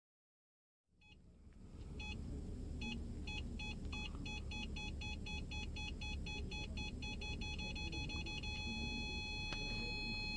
На этой странице собраны звуки парктроника — сигналы, которые издает автомобиль при парковке или обнаружении препятствий.
Парктроник срабатывает и подает звук при движении назад